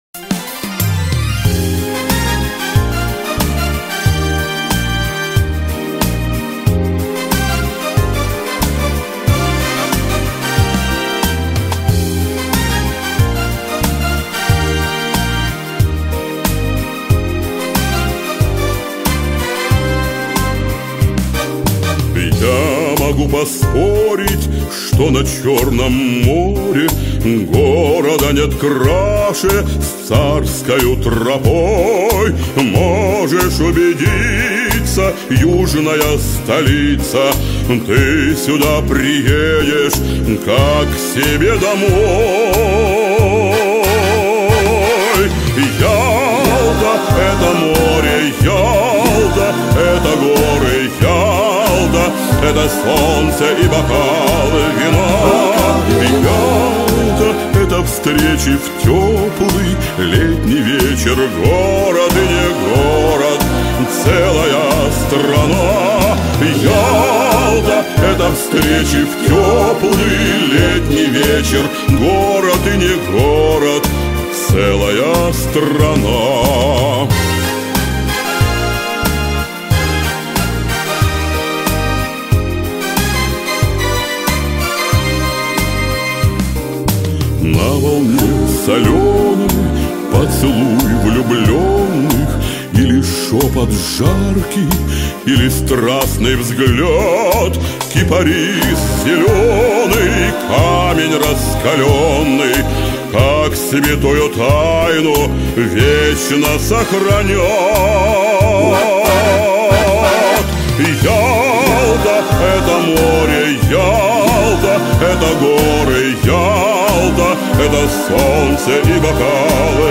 официальную торжественную композицию